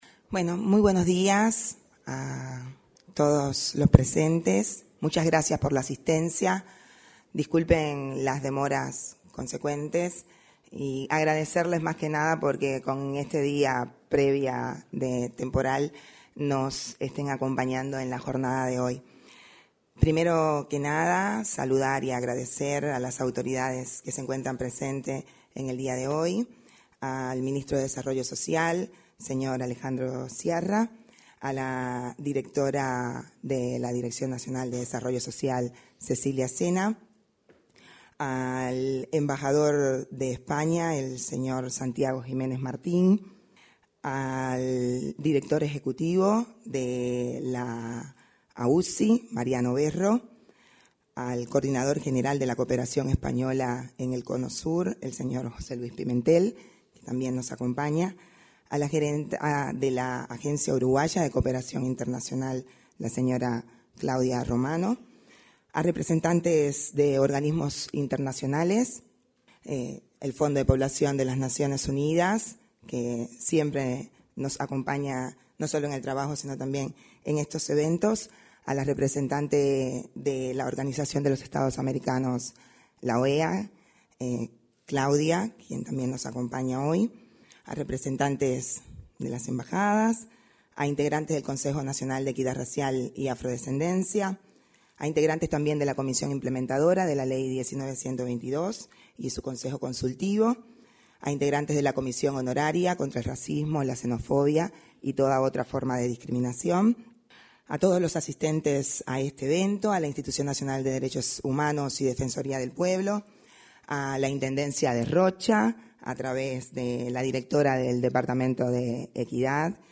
En la apertura de la presentación de herramientas para una ciudadanía activa, participaron el titular del Ministerio de Desarrollo Social (Mides),